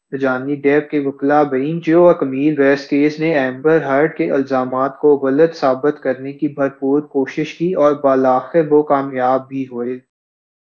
Spoofed_TTS/Speaker_13/262.wav · CSALT/deepfake_detection_dataset_urdu at main